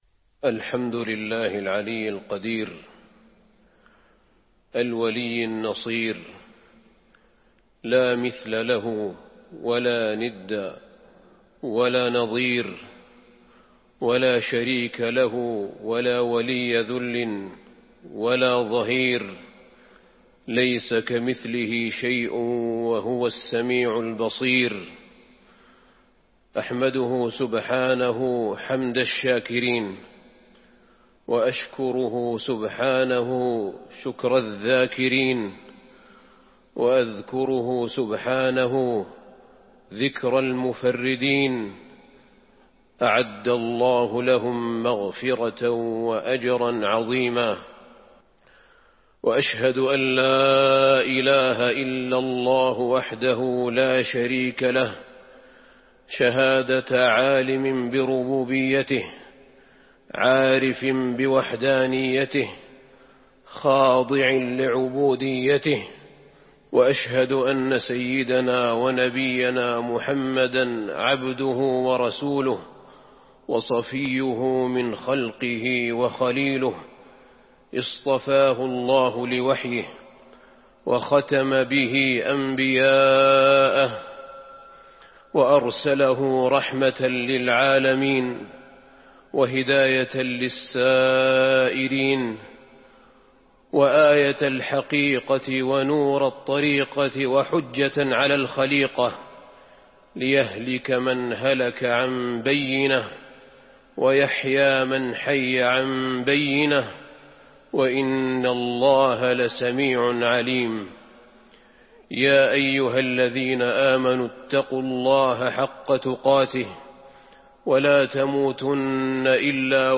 تاريخ النشر ٩ جمادى الآخرة ١٤٤٢ هـ المكان: المسجد النبوي الشيخ: فضيلة الشيخ أحمد بن طالب بن حميد فضيلة الشيخ أحمد بن طالب بن حميد أولوا الألباب